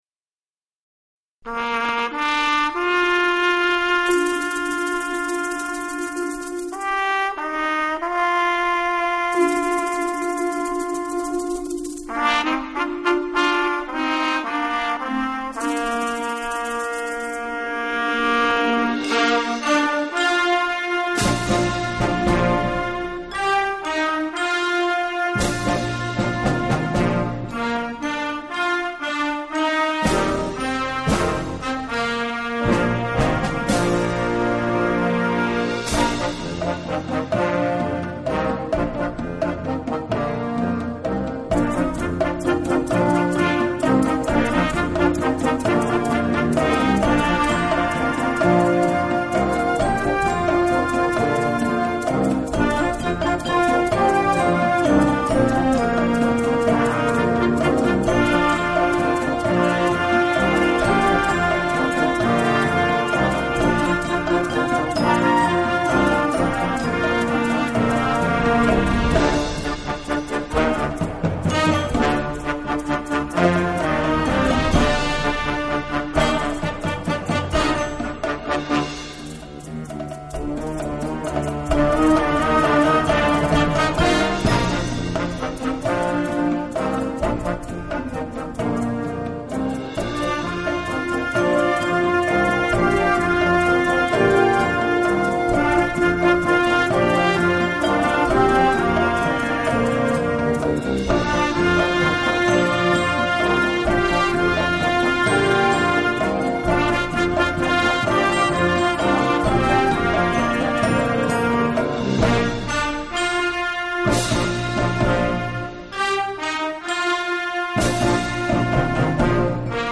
◎　デモ音源（ＭＰ３ファイル）
ナヴァロ　　　(吹奏楽)